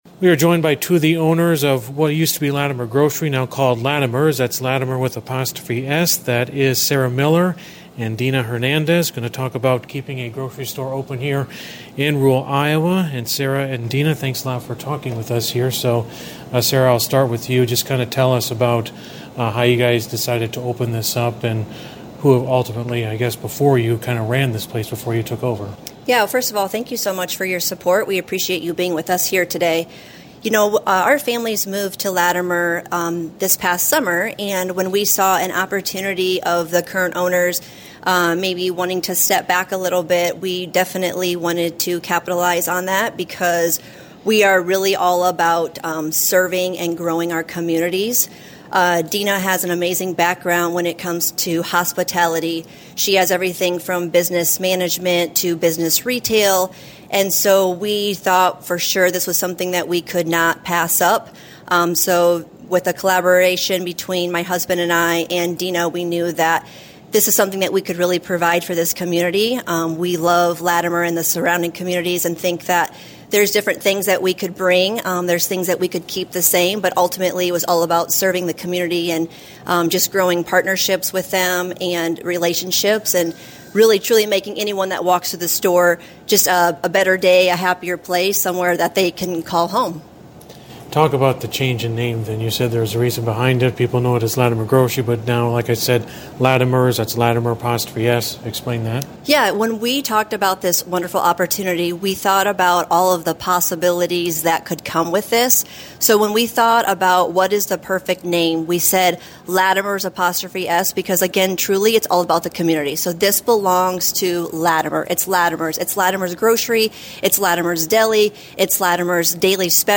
Full interview below